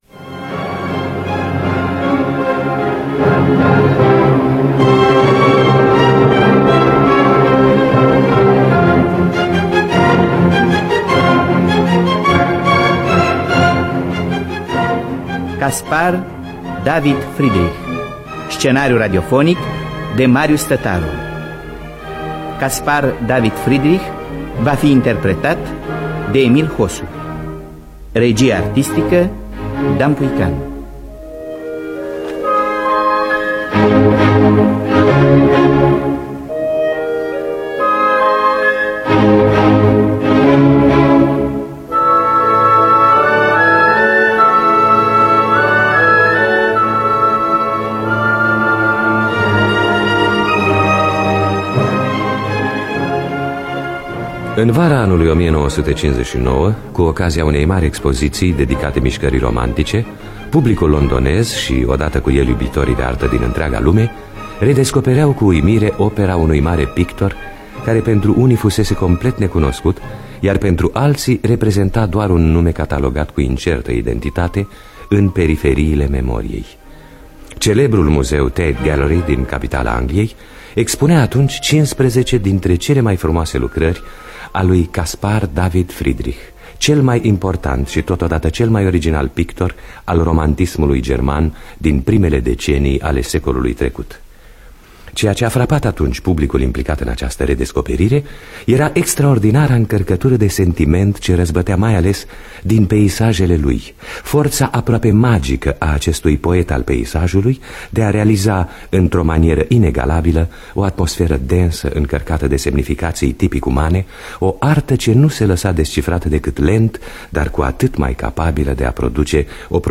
Biografii, Memorii: Caspar-David Friedrich – Teatru Radiofonic Online